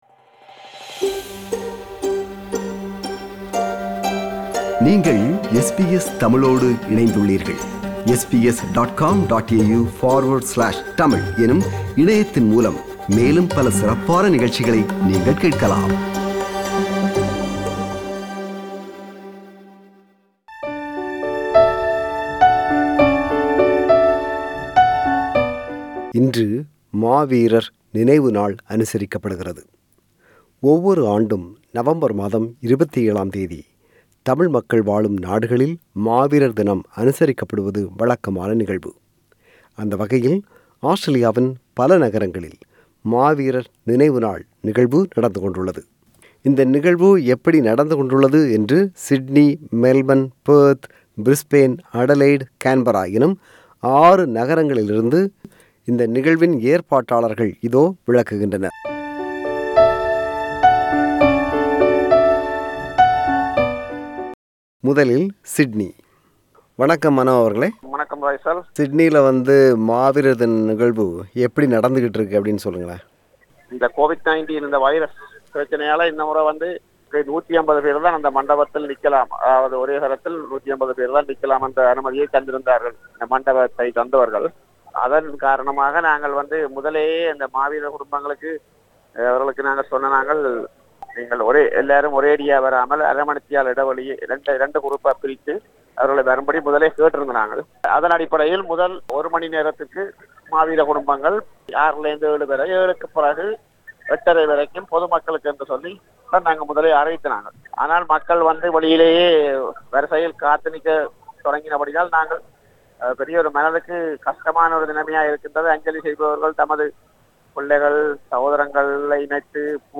The organisers of the events spoke to SBS Tamil.